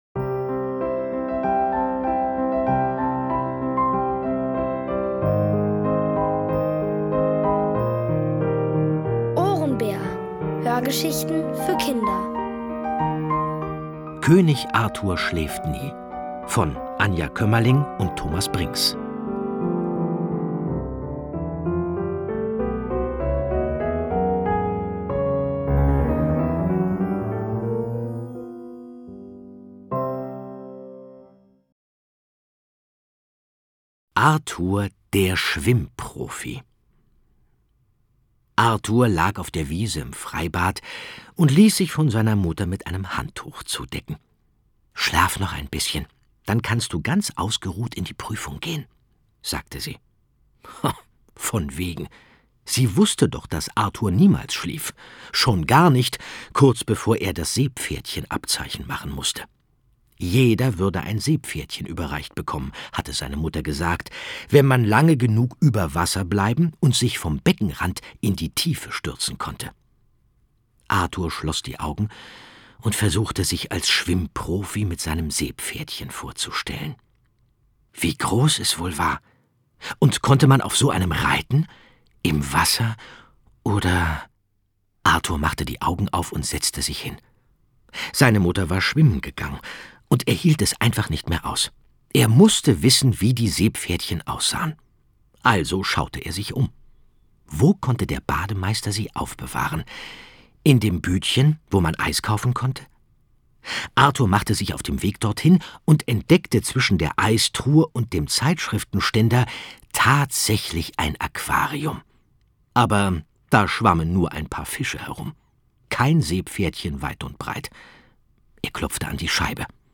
Von Autoren extra für die Reihe geschrieben und von bekannten Schauspielern gelesen.
Es liest: Andreas Fröhlich.